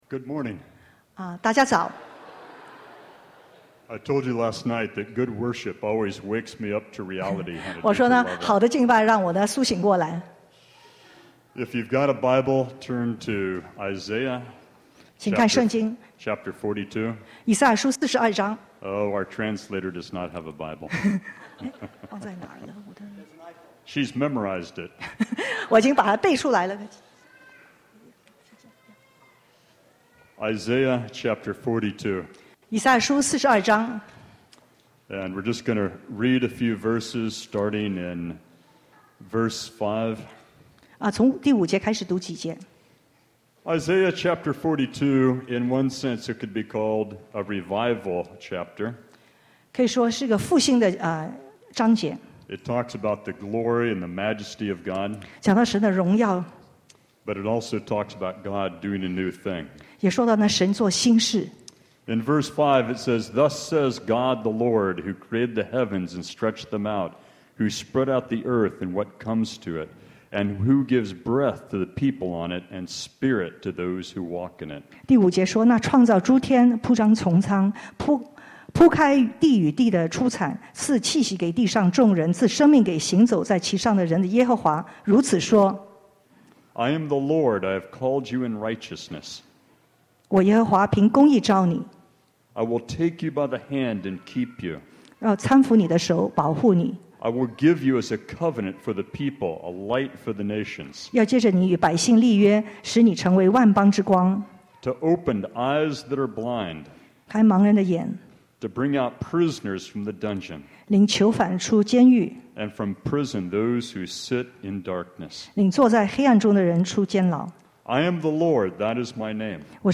复兴特会四（2016-08-14）